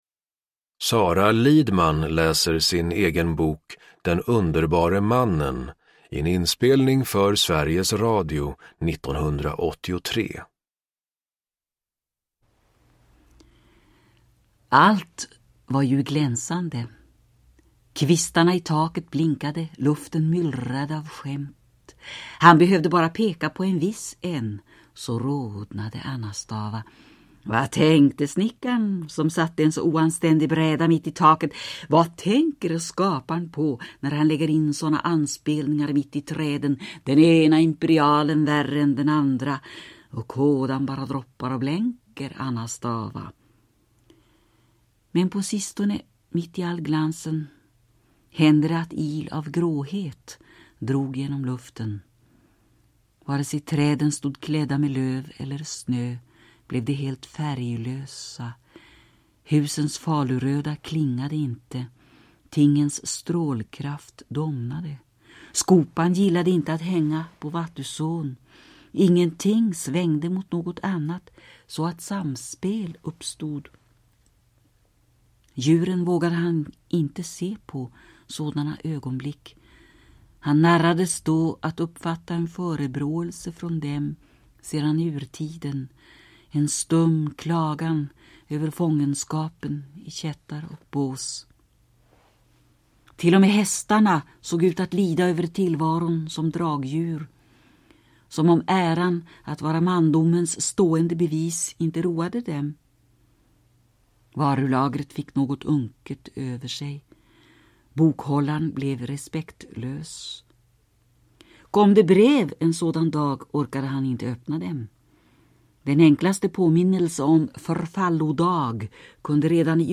Uppläsare: Sara Lidman
Ljudbok
Utgiven i samarbete med Sverigs Radio.